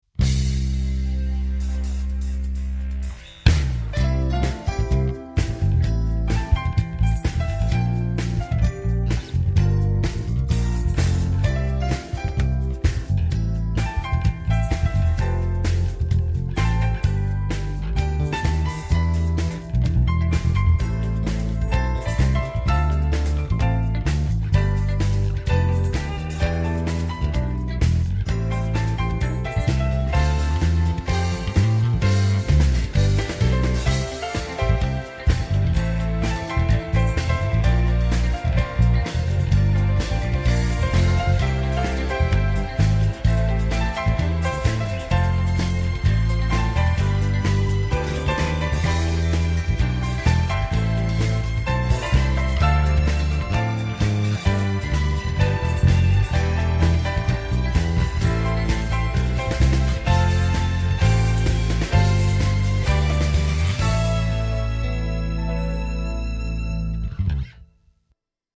Hab jetzt den Shortscale Bornit mit Flats / TV Jones PU genommen und bin überrascht wie voll das klingt Wobei das nicht an Deine Lässigkeit rankommt, das ist schon ganz großes Kino Anhänge ALF_DL.mp3 1,1 MB